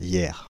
Yerres (French pronunciation: [jɛʁ]
Fr-Paris--Yerres.ogg.mp3